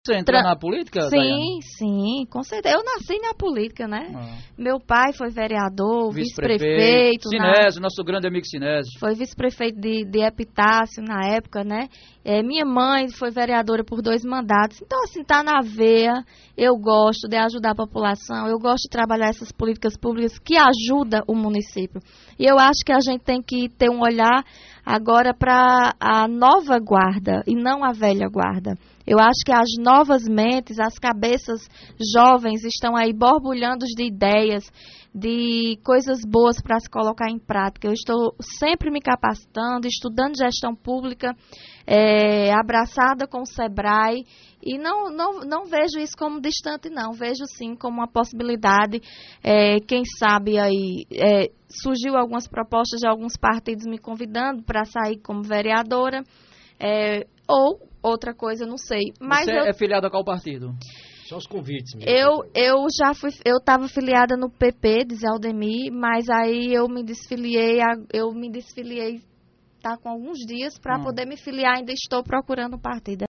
Esse é o proposito da atual Secretária de Desenvolvimento Econômico da gestão municipal de Cajazeiras, Daine Oliveira, em sua participação na última sexta – feira (03) no programa Rádio Vivo da Alto Piranhas.